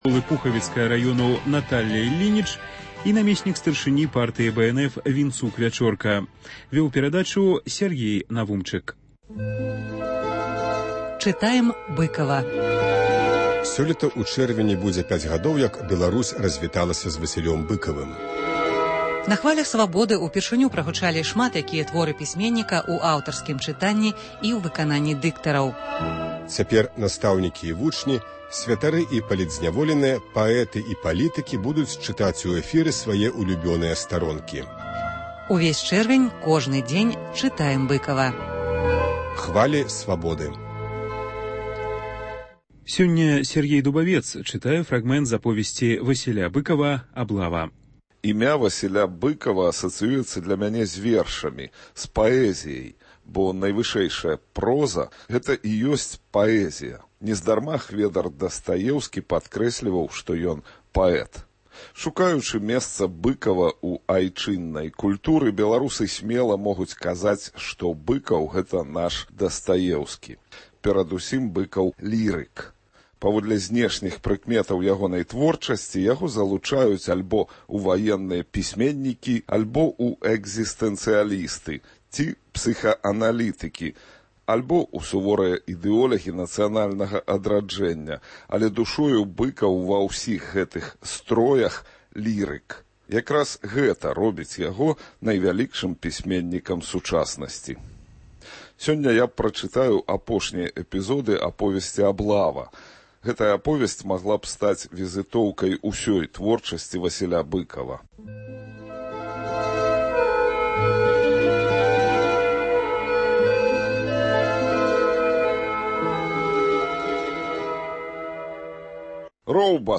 Агляд тэлефанаваньняў слухачоў за тыдзень.